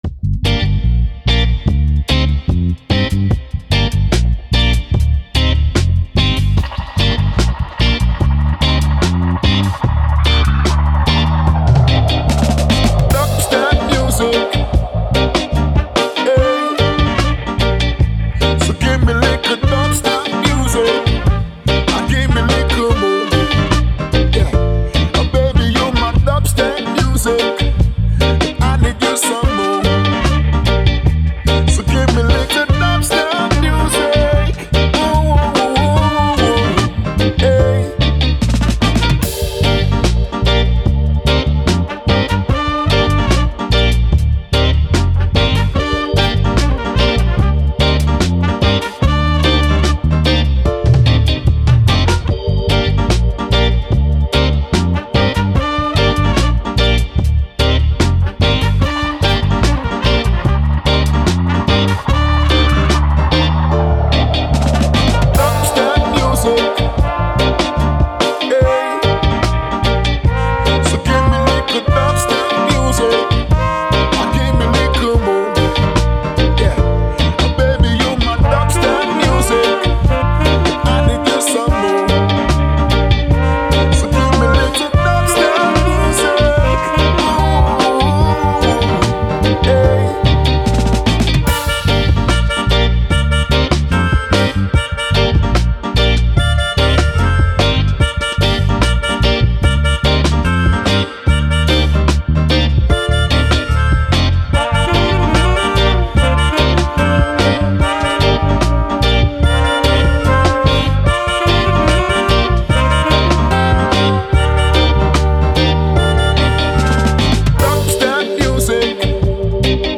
Reggae, Dub, Vibe, Sun